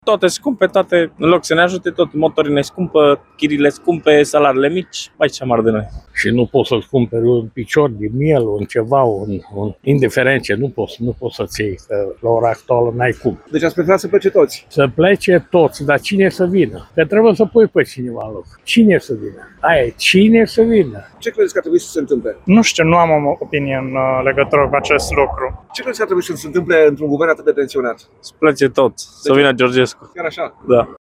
„Să plece toți, să vină Georgescu”, a spus un alt bărbat